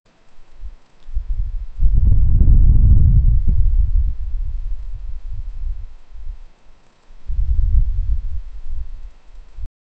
A deep, eerie elemental creature made of swirling water and wind, emitting haunting, hollow sounds. Slow, fluid movement with a constant vortex-like whooshing. Layered with low-frequency rumbling, wet suction noises, and distorted aquatic echoes.
low-rumble-dusty-wind-fai-haozfj7d.wav